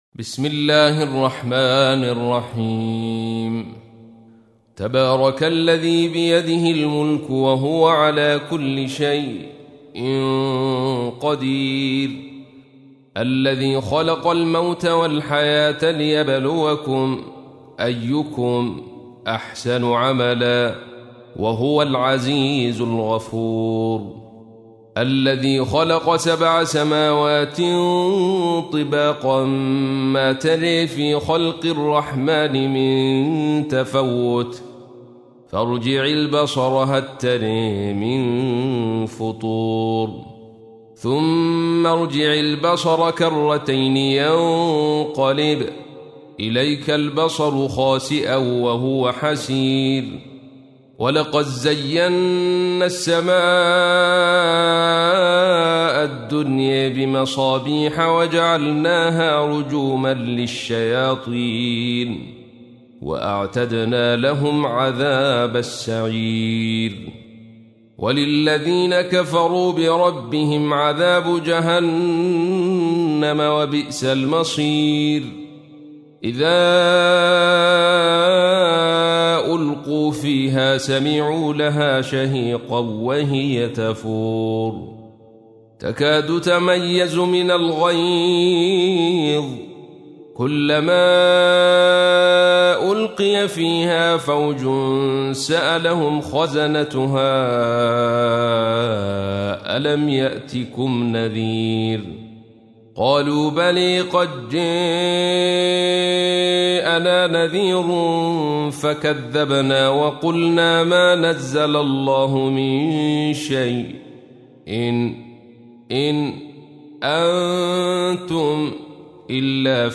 تحميل : 67. سورة الملك / القارئ عبد الرشيد صوفي / القرآن الكريم / موقع يا حسين